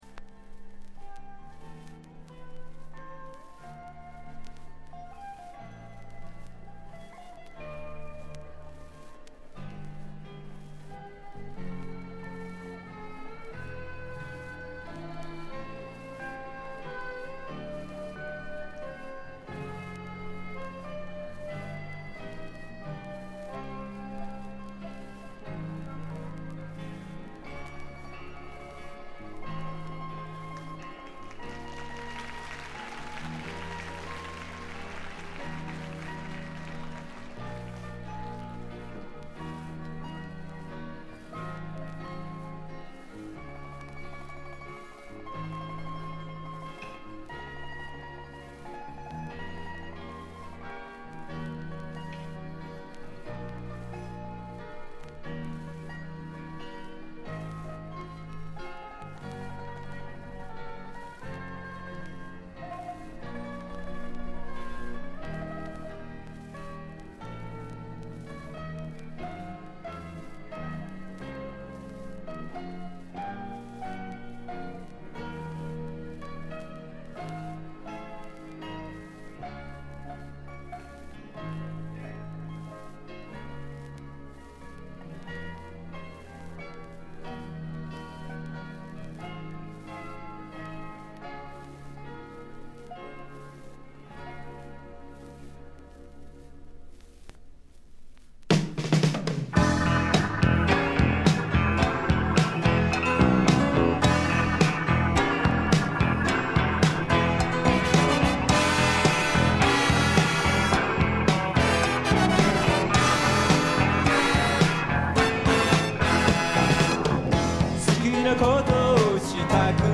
つまみ聴き試聴するとA面冒頭の導入部のみチリプチが出ますが、他は良好だと思います。
試聴曲は現品からの取り込み音源です。